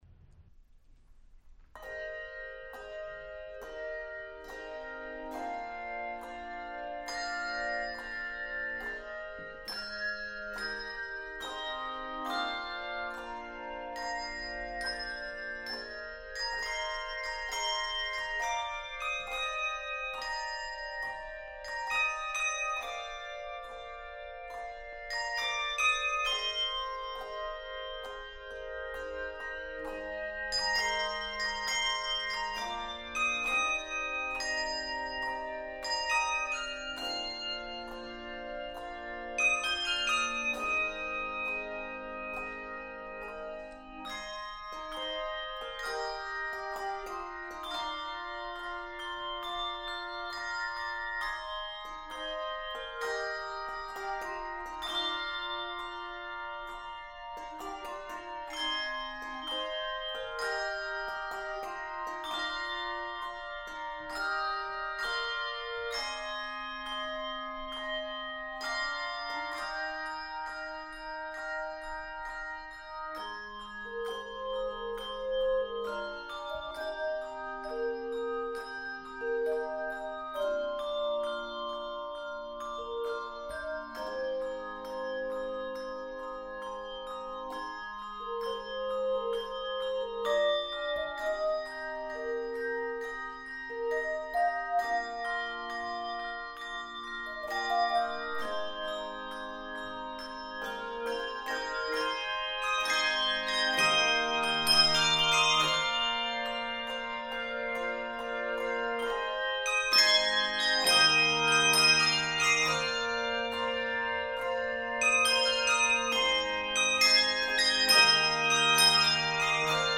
contemporary Christian song
Octaves: 3-5